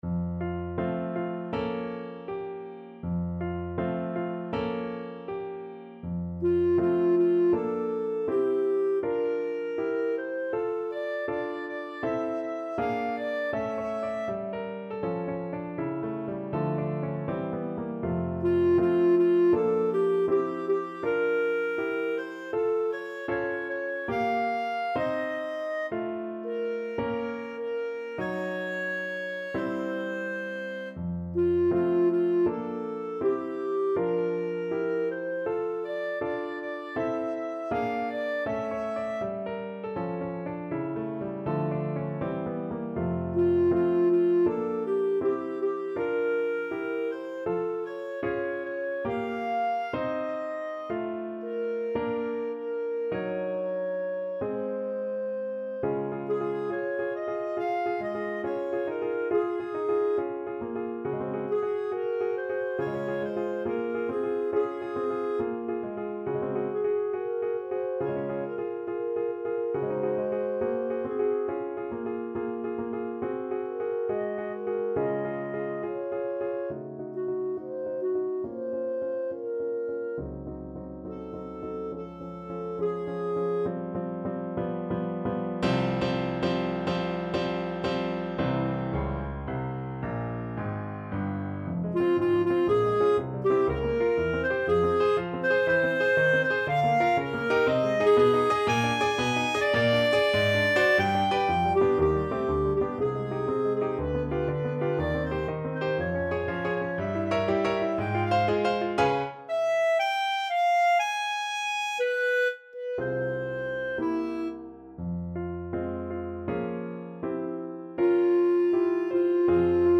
Moderato =80
2/4 (View more 2/4 Music)
Classical (View more Classical Clarinet Music)